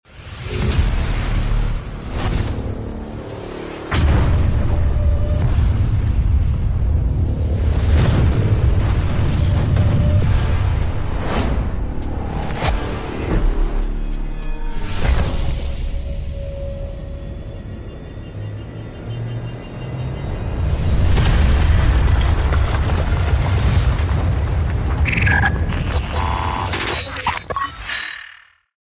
Cinematic Sound Effects Bundle! More Sound Effects Free Download